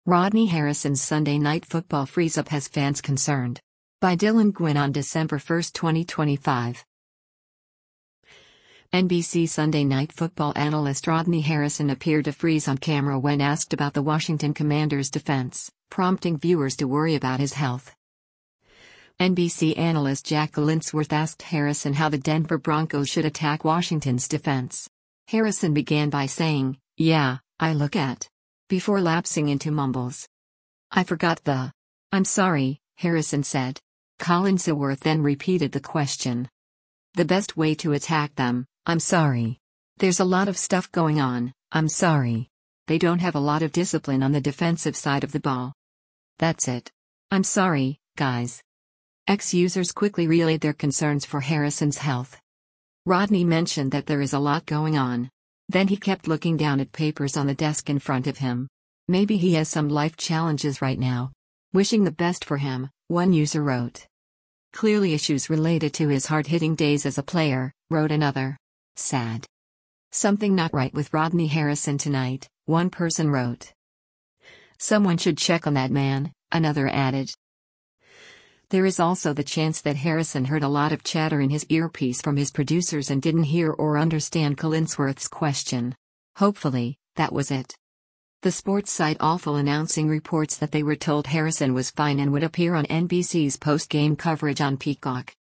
Harrison began by saying, “Yeah, I look at…” before lapsing into mumbles.